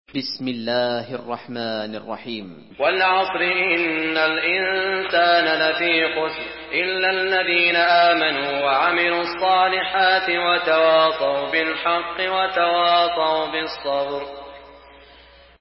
Surah Asr MP3 in the Voice of Saud Al Shuraim in Hafs Narration
Murattal Hafs An Asim